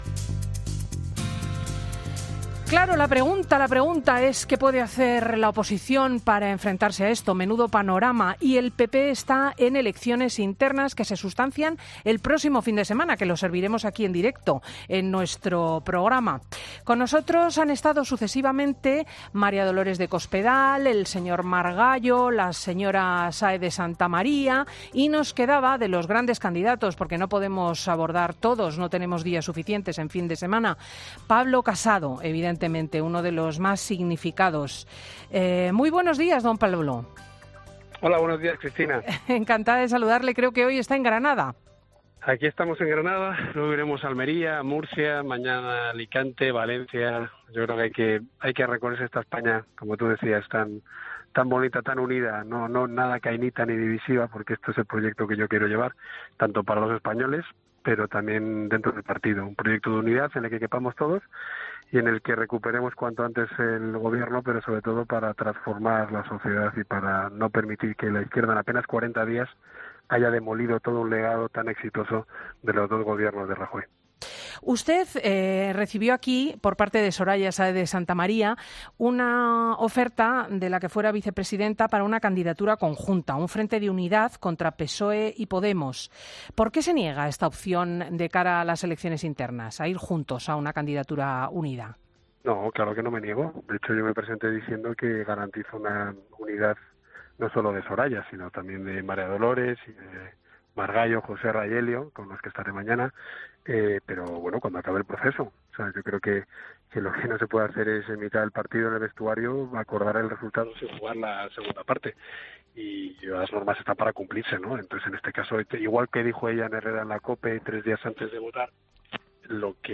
El candidato a las primarias del PP critica en el Fin de Semana de COPE el Tratado de Schengen